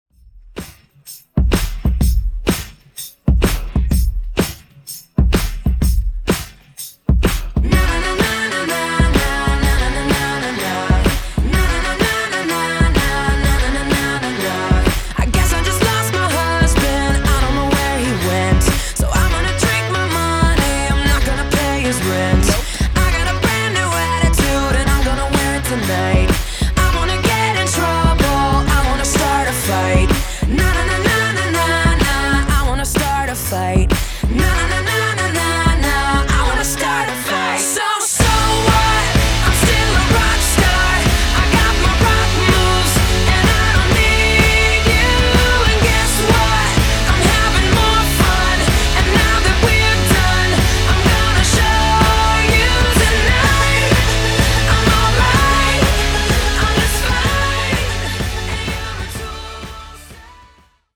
Genre: MASHUPS
Clean BPM: 103 Time